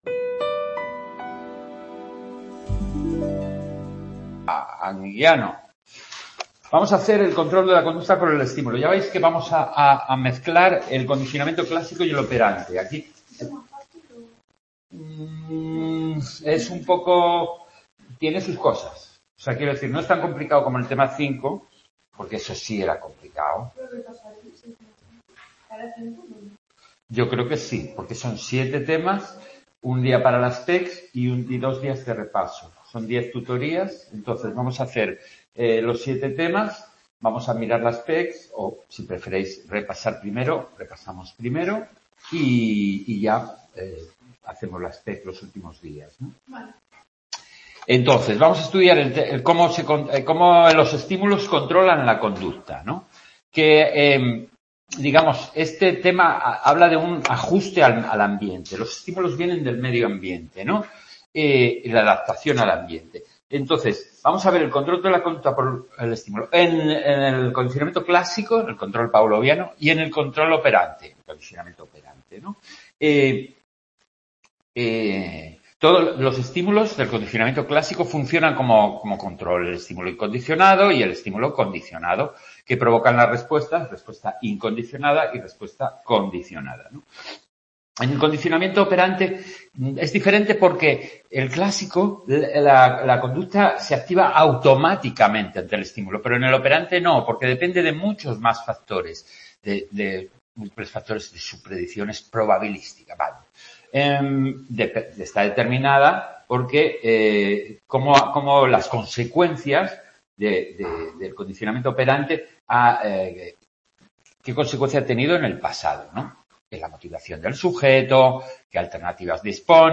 en el CA de Sant Boi